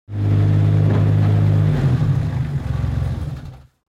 دانلود آهنگ تراکتور 3 از افکت صوتی حمل و نقل
دانلود صدای تراکتور 3 از ساعد نیوز با لینک مستقیم و کیفیت بالا
جلوه های صوتی